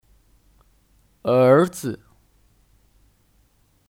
儿子 (Érzi 儿子)